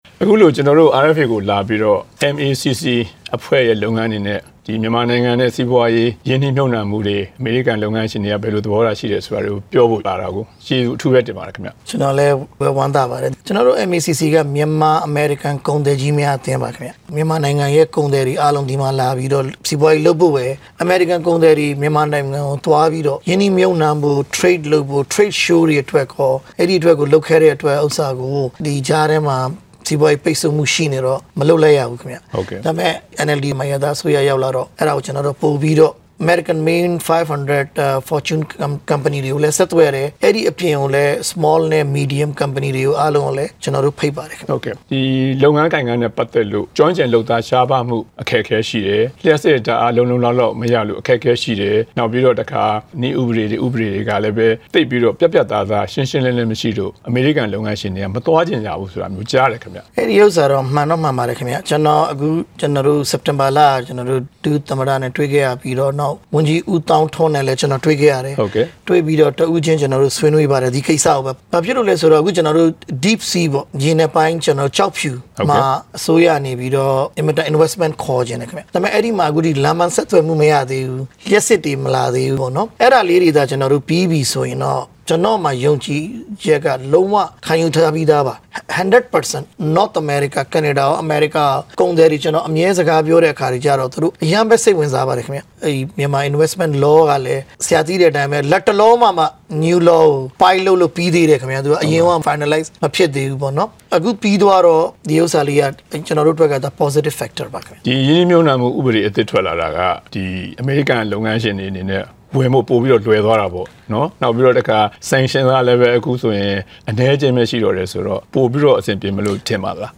မေးမြန်းချက်
RFA studio မှာ တွေ့ဆုံမေးမြန်းထားတာ နားဆင်နိုင်ပါတယ်။